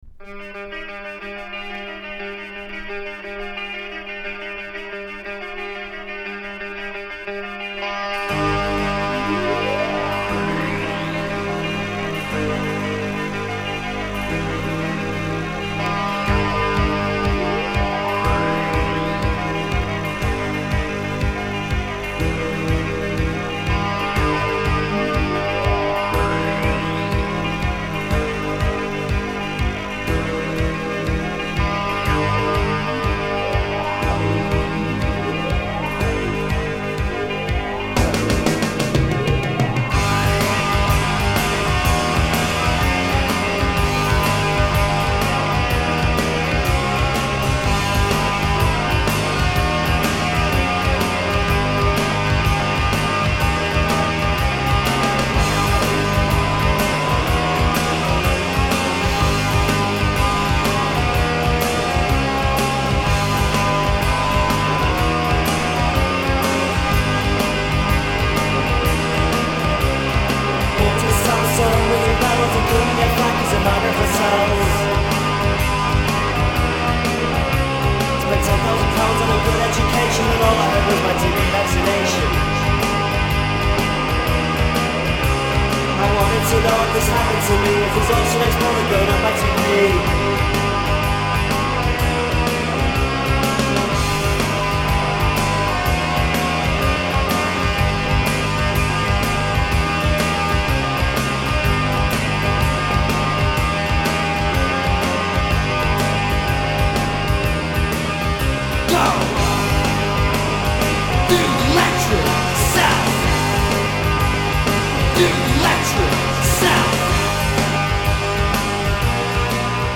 Psychedelia and modernity.